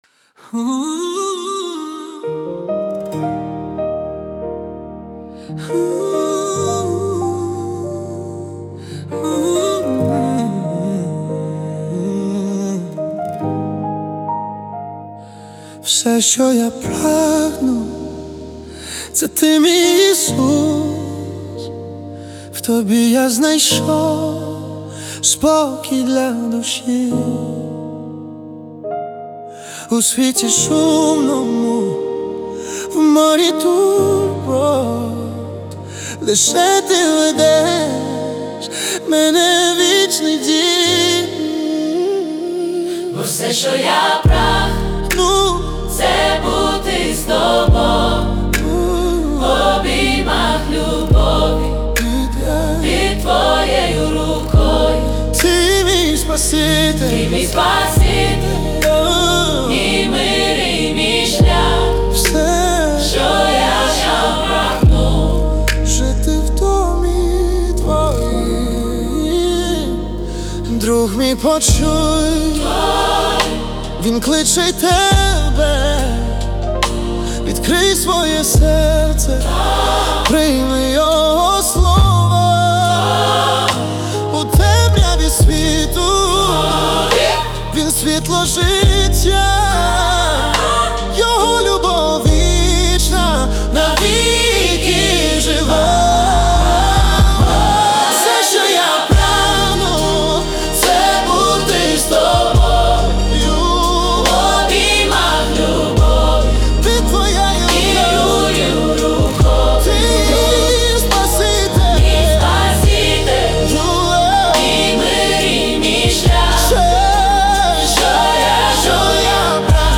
песня ai
600 просмотров 819 прослушиваний 46 скачиваний BPM: 70